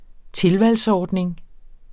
Udtale [ ˈtelvals- ]